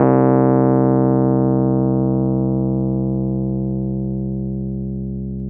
RHODES-C1.wav